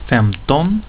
femton